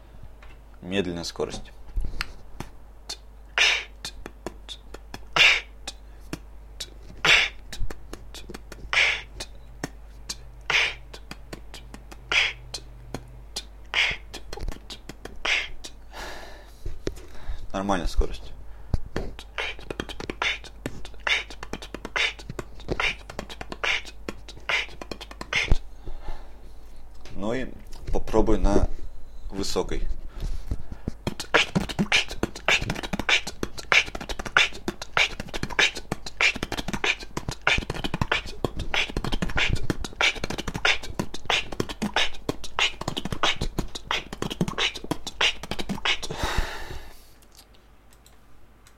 Форум российского битбокс портала » Реорганизация форума - РЕСТАВРАЦИЯ » Выкладываем видео / аудио с битбоксом » Вроде как ДНБ (Прикольно звучит, но на ДНБ скорости сделать не смог.)
Вроде как ДНБ
b t kch tbbtbbkch t
правда изначально было без первого хай-хэта.
Да у меня микрофон компьютерный, а не вокальный, басов нету, звуки не звучат(